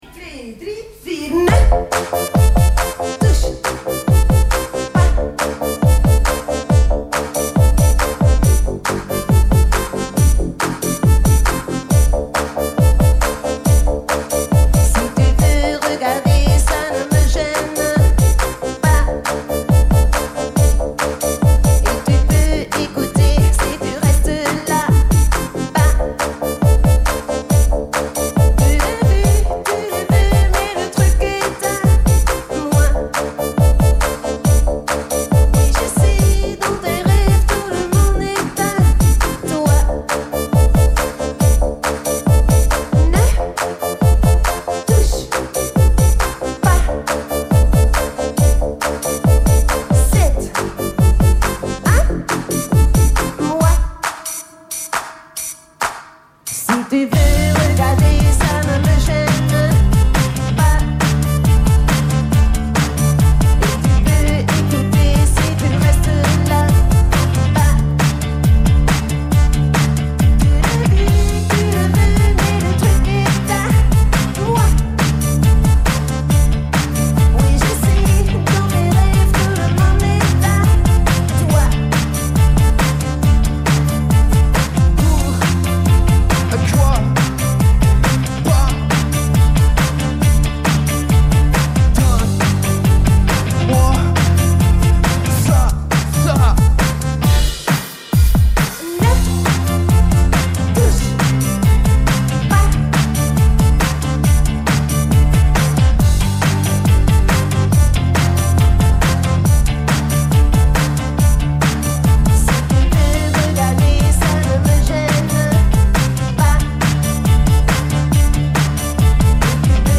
enregistrée le 28/06/2004  au Studio 105